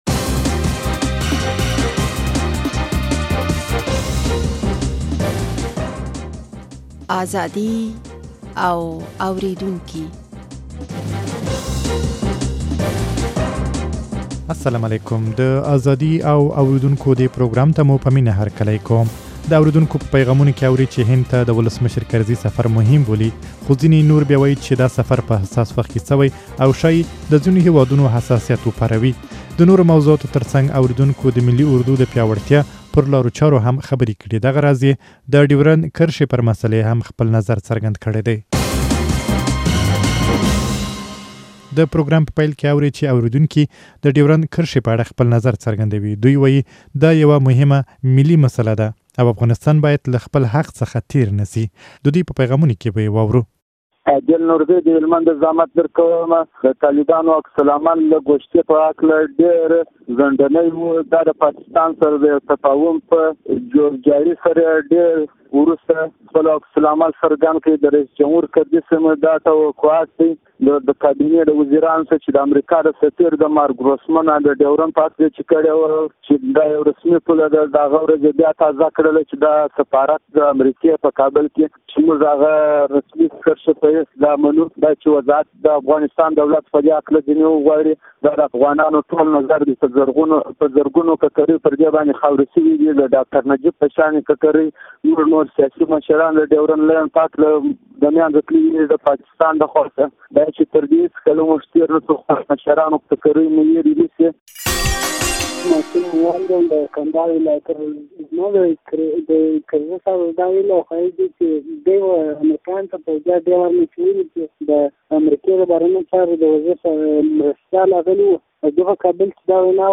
د اورېدونکو په پيغامونو کې اورئ چې هند ته د کرزي سفر مهم بولي خو ځينې نور بيا وايي دا سفر په حساس وخت کې شوى او ښايي د ځينو هېوادونو حساسيت وپاروي.